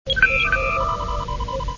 Star Trek Sounds
-------- Raumschiff wird gerufen